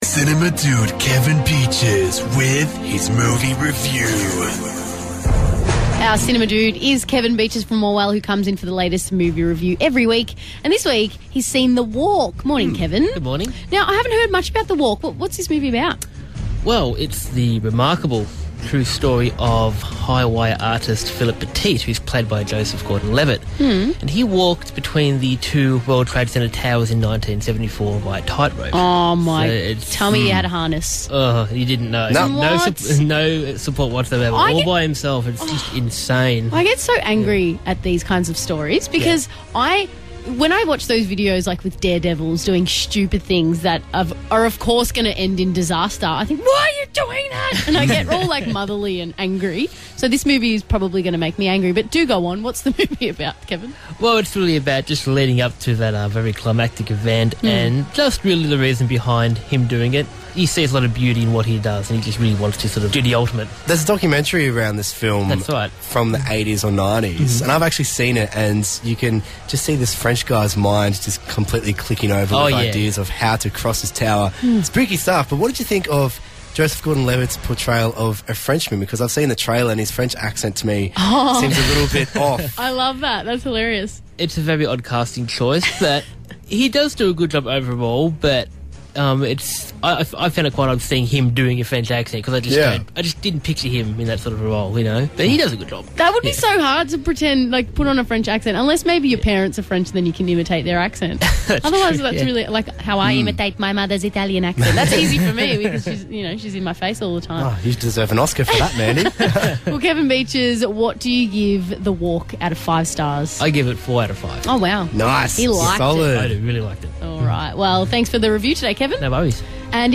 Review: The Walk (2015)